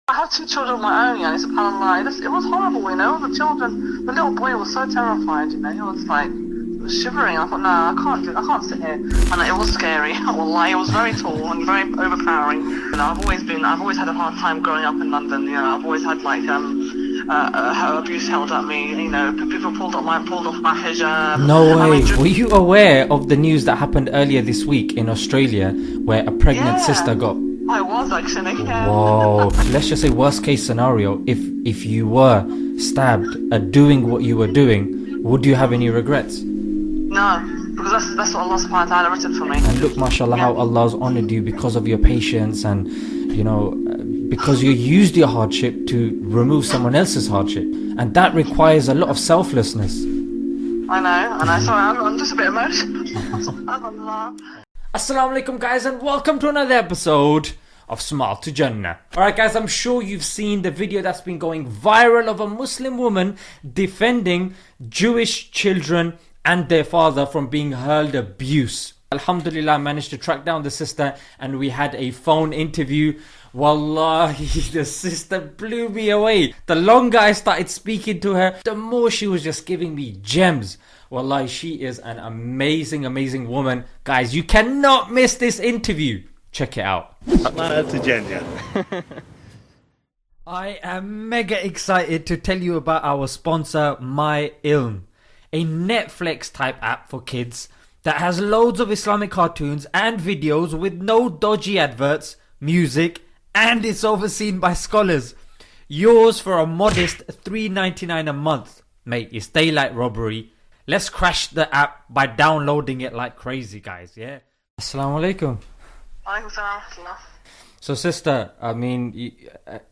(INTERVIEW) MUSLIM WOMAN WHO DEFENDED JEW.mp3